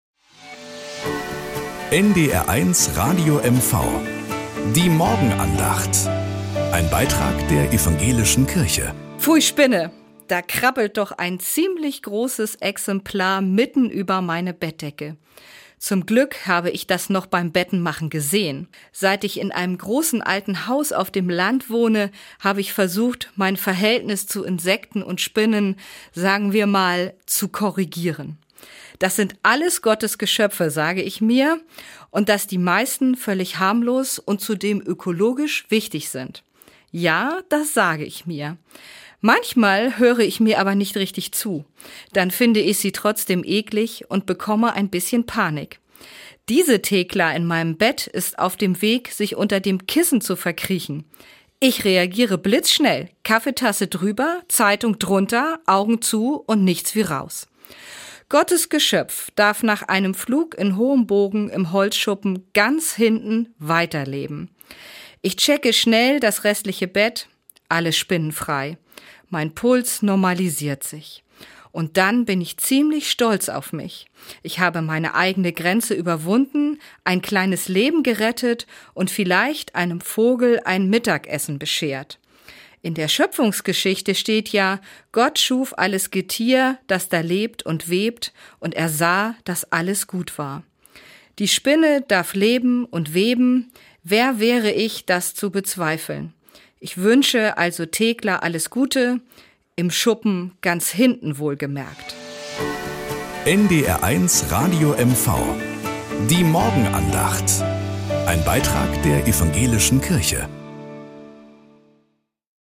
Morgenandacht bei NDR 1 Radio MV
Um 6:20 Uhr gibt es in der Sendung "Der Frühstücksclub" eine